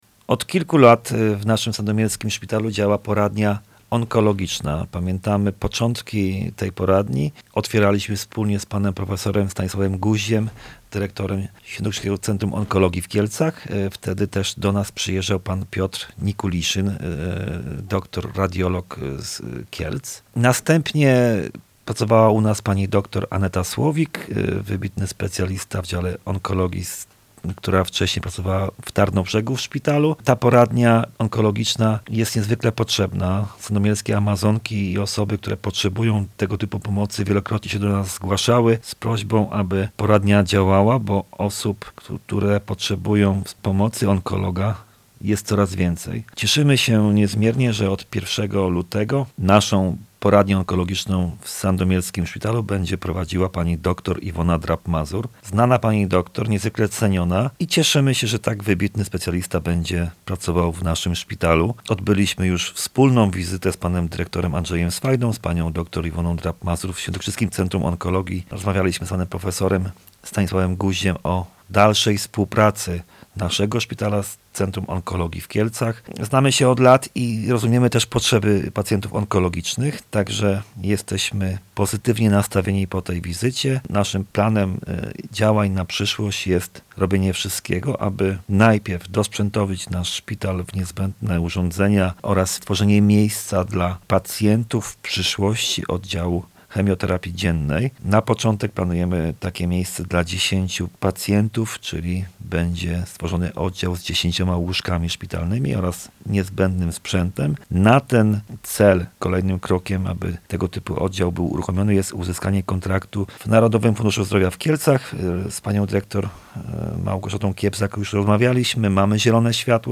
O szczegółach i najbliższych planach opowiedział Radiu Leliwa starosta sandomierski Marcin Piwnik: